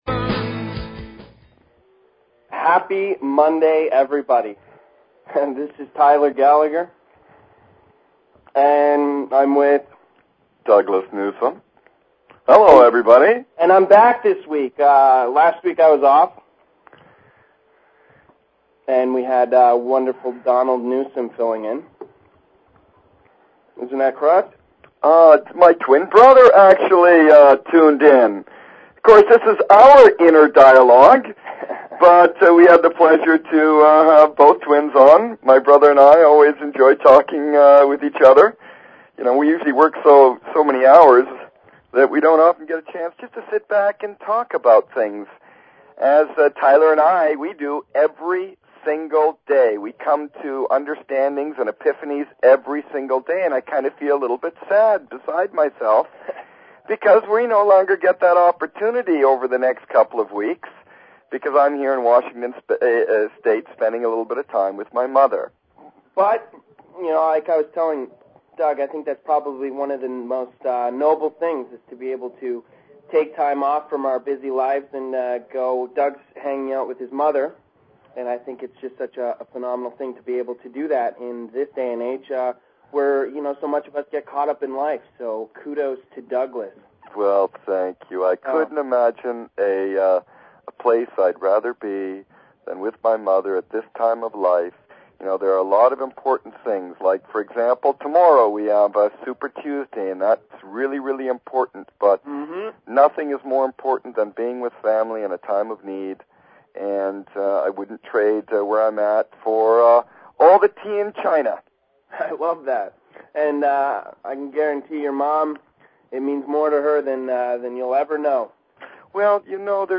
Talk Show Episode, Audio Podcast, Inner_Dialogue and Courtesy of BBS Radio on , show guests , about , categorized as